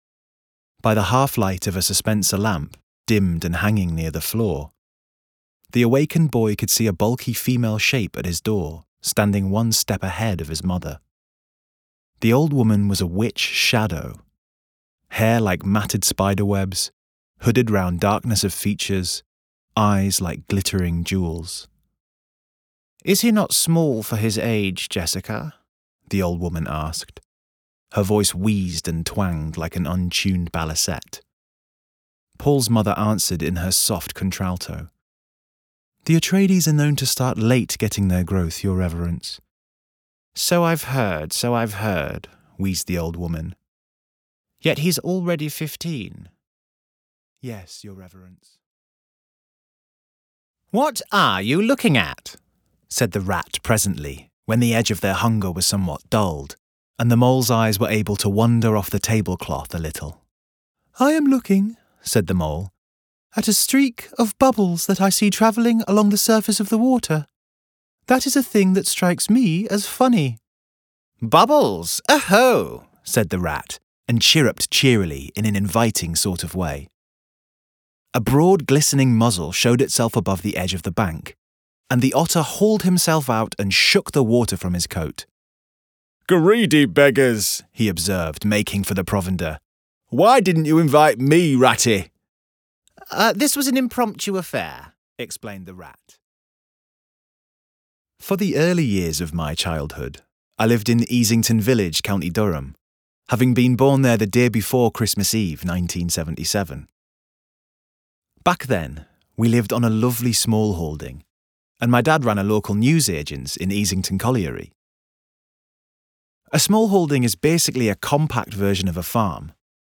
1202Audiobook_Reel__3_min_.mp3